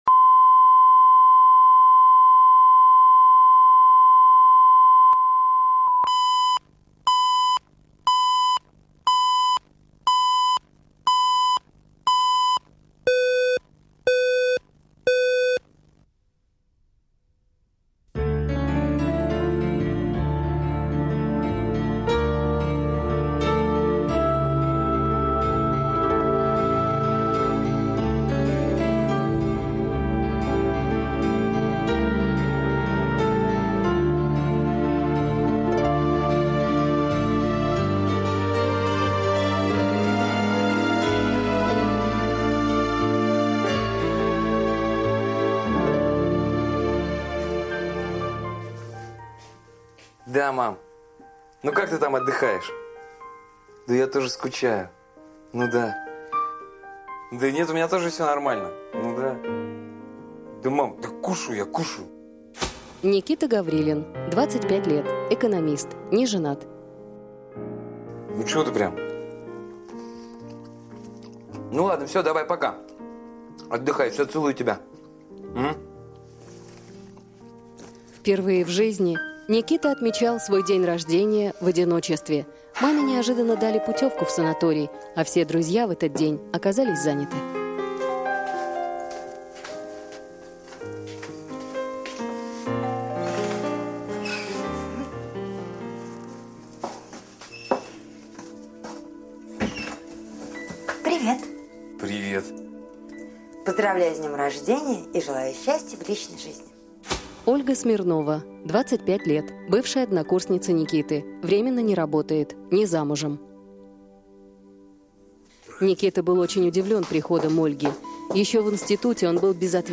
Аудиокнига Подарочек | Библиотека аудиокниг